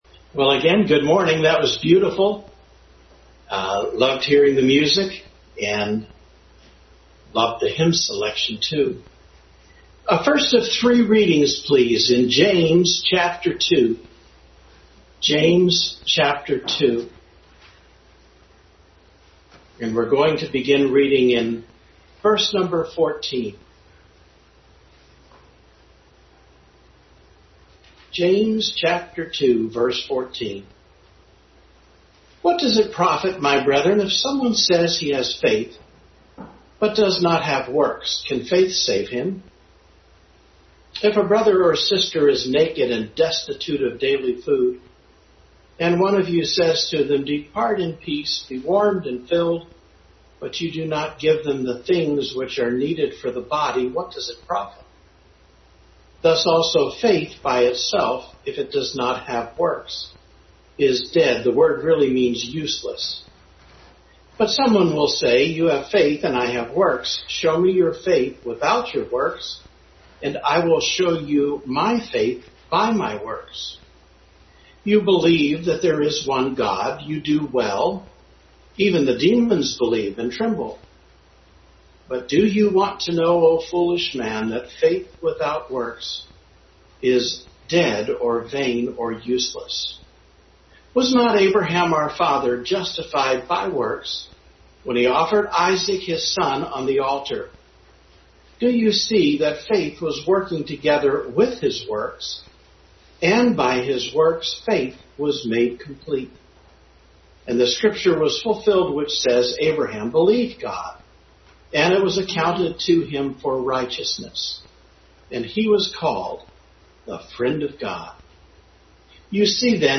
Bible Text: James 2:14-26, Philippians 4:18-20, John 14:1-6 | Family Bible Hour Message.